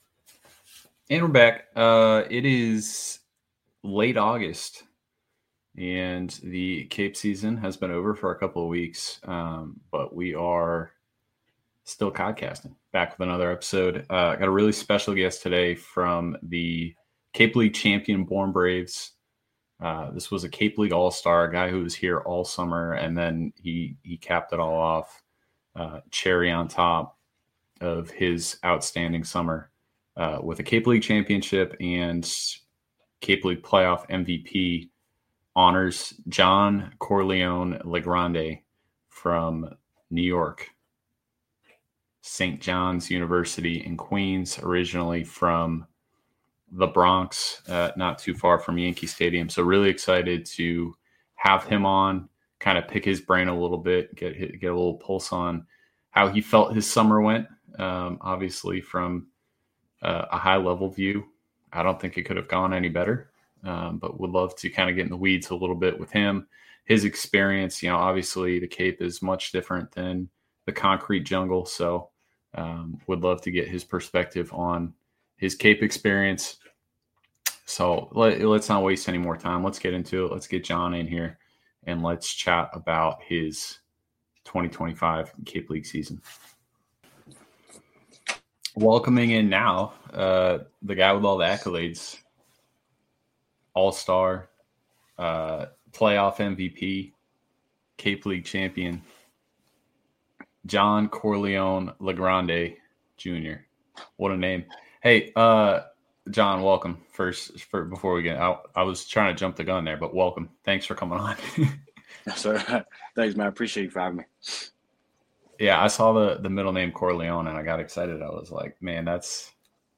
Keep current with stories unique to the Cape Cod Baseball League experience; news on players; interviews with coaches; and more!